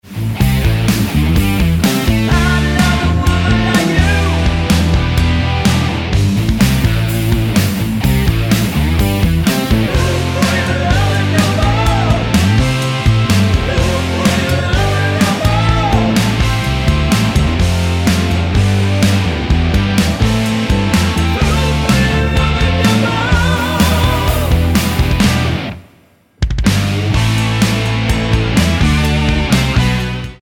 --> MP3 Demo abspielen...
Tonart:A mit Chor